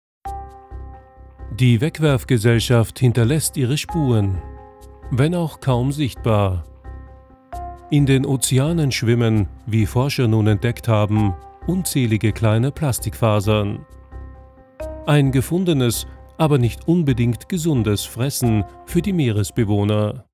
Deutscher Sprecher, Off Sprecher,eigenes Studio vielseitige,warme, durchsetzungsfähige Stimme für Werbung, Dokumentation, Fernsehbeiträge, Trailer,e-learning, Imagefilm, Lyrik und Hörbuch
Sprechprobe: Industrie (Muttersprache):
versatile german voice over artist
demo doku science.mp3